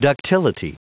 Prononciation du mot ductility en anglais (fichier audio)
Prononciation du mot : ductility